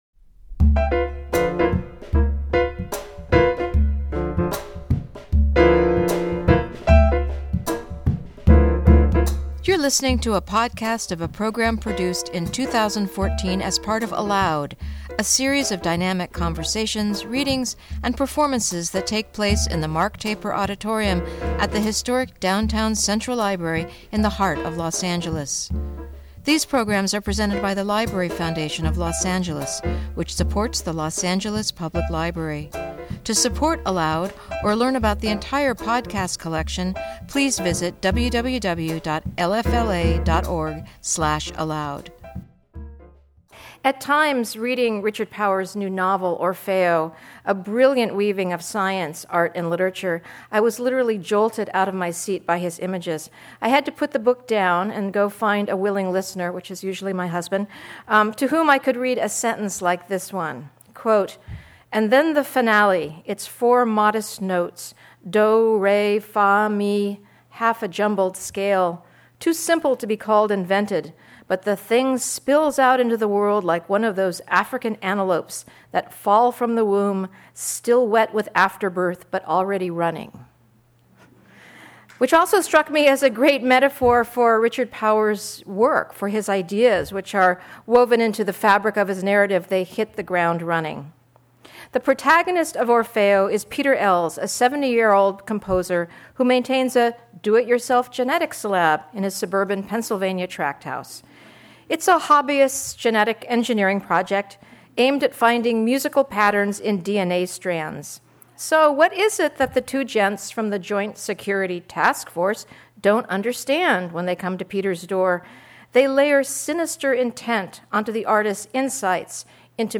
Richard Powers In Conversation With Michael Silverblatt, host of KCRW's "Bookworm"